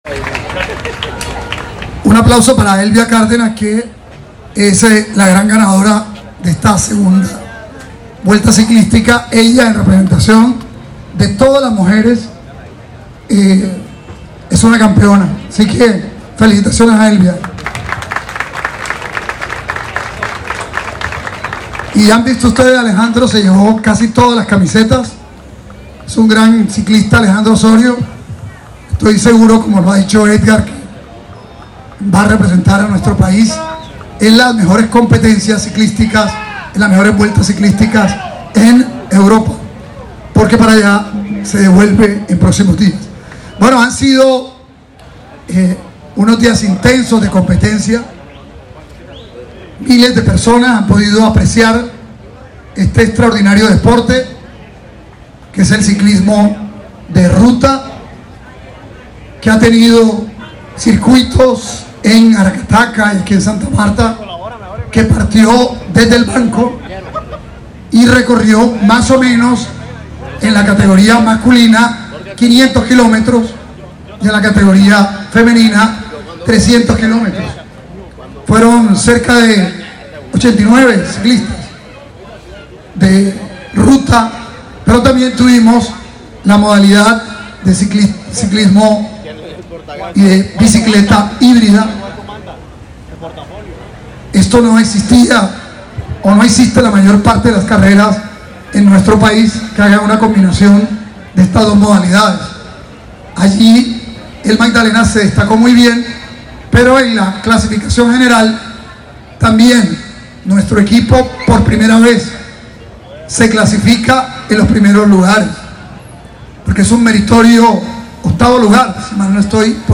De manos del gobernador Carlos Caicedo Omar se hicieron las entregas de los reconocimientos a los triunfadores de esta competencia, que en esta ocasión tuvo la participación de más de 170 ciclistas provenientes de diferentes regiones del Colombia.
Carlos-Caicedo-gobernador-del-Magdalena-mp3cut.net_.mp3